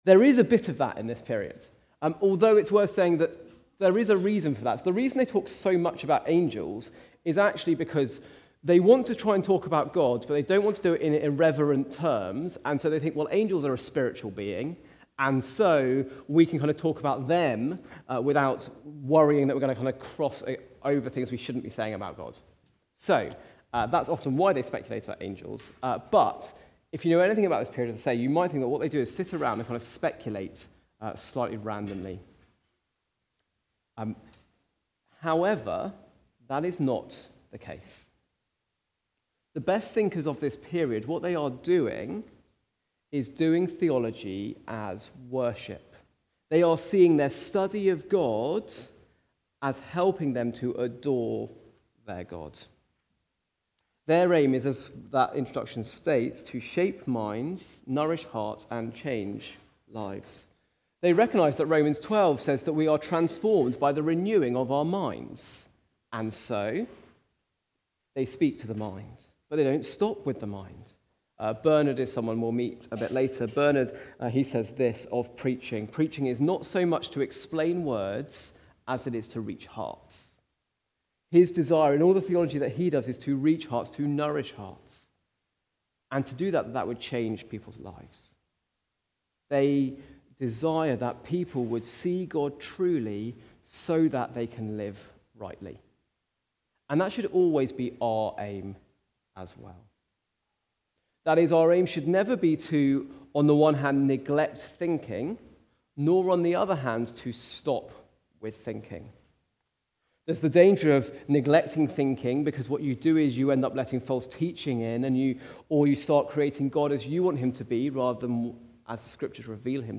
Part III - Gazing at Our Great God from the series Learning From Our Forefathers. Recorded at Woodstock Road Baptist Church on 08 February 2026.